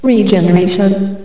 regeneration.wav